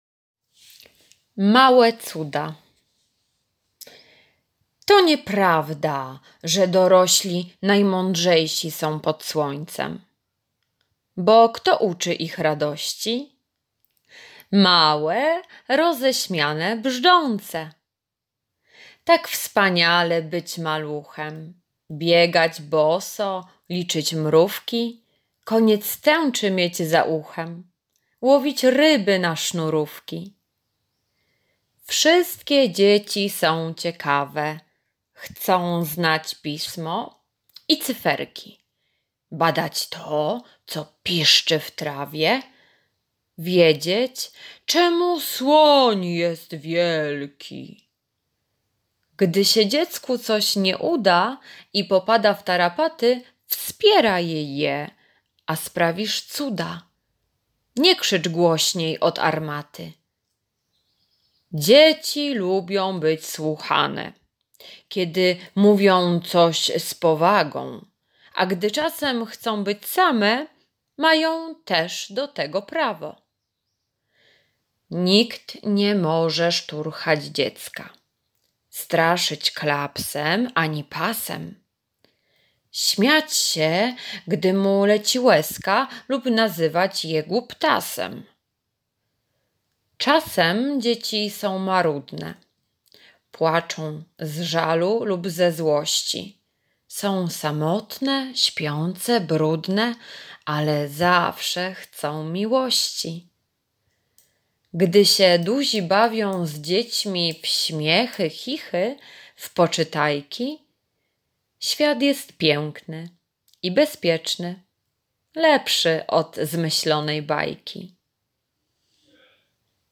poniedziałek- wiersz "Małe Cuda" [2.19 MB] poniedziałek- prezentacja "Dzieci z różnych stron świata" [7.78 MB] poniedziałek- kolorowanka "Dzień Dziecka" [77.48 kB] poniedziałek - ćw. dla chętnych - litera S, s [108.53 kB]